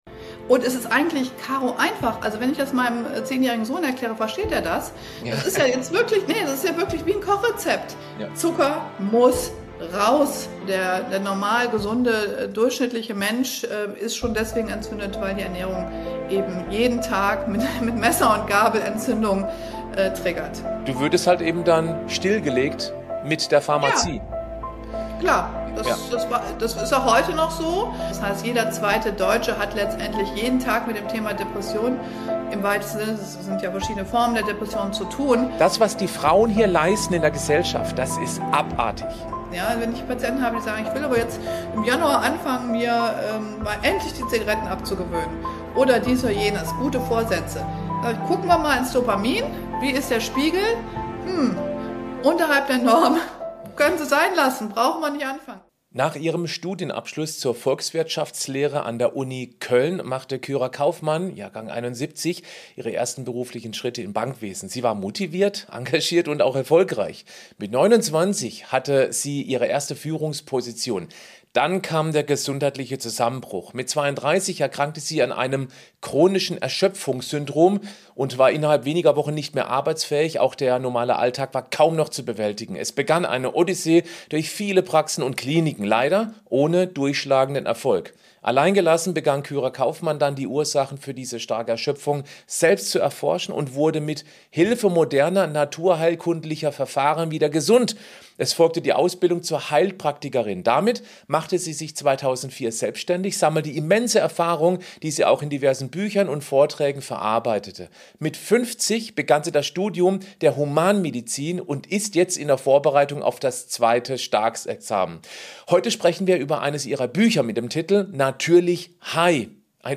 In diesem Interview erfährst du, wie es ganz natürlich möglich ist, einen dauerhaften High-Zustand zu erfahren, um glücklich und erfolgreich durchs Leben gehen zu können!